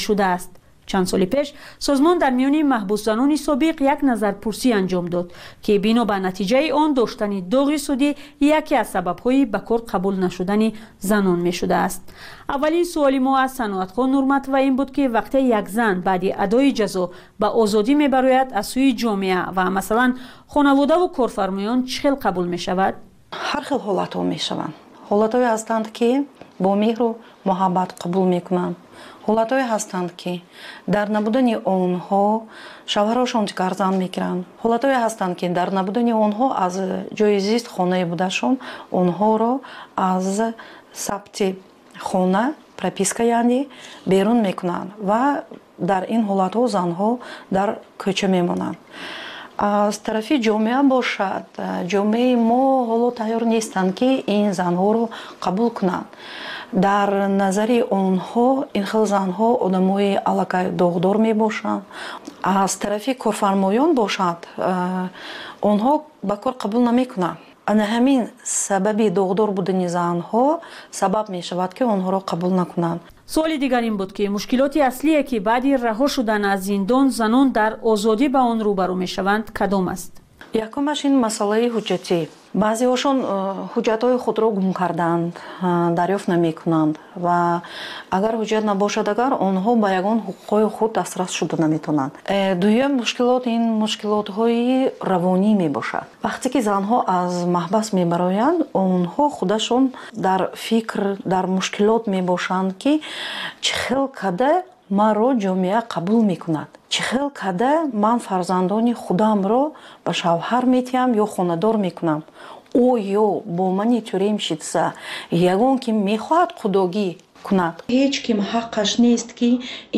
Пахши зинда
Тозатарин ахбор ва гузоришҳои марбут ба Тоҷикистон, минтақа ва ҷаҳон дар маҷаллаи шомгоҳии Радиои Озодӣ.